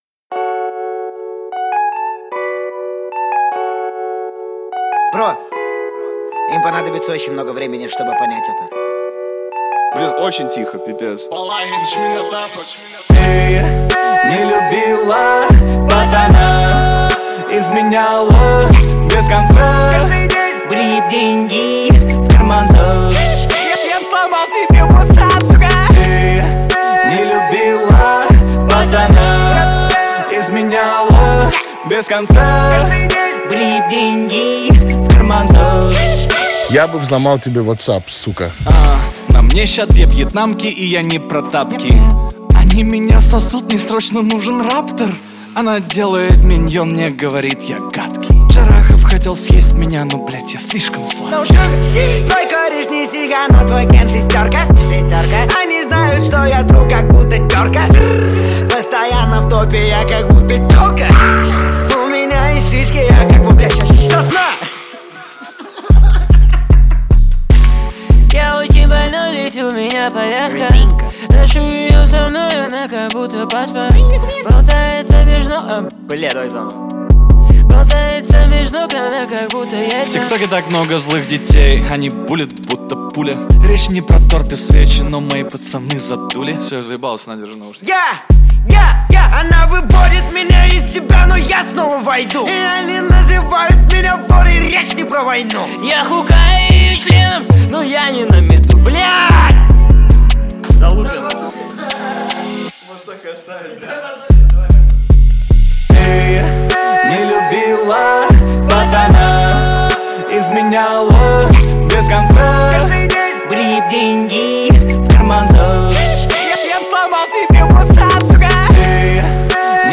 Хип-хоп
Жанр: Хип-хоп / Русский рэп